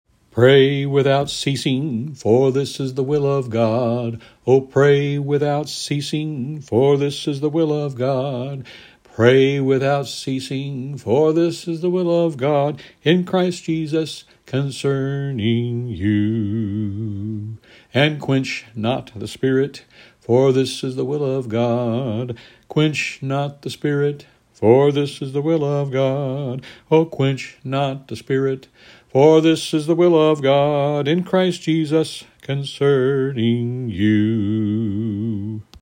A song to remember: Just one more verse of me singing: Quench not the Spirit: